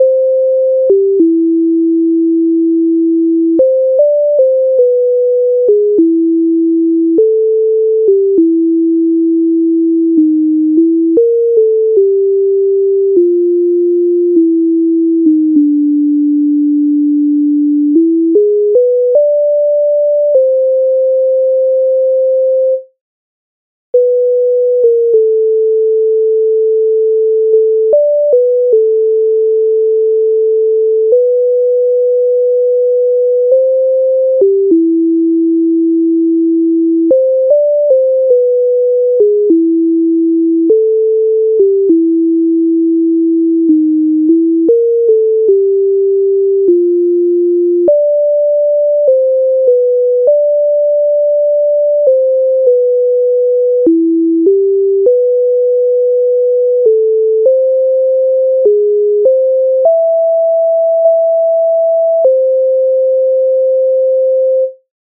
MIDI файл завантажено в тональності C-dur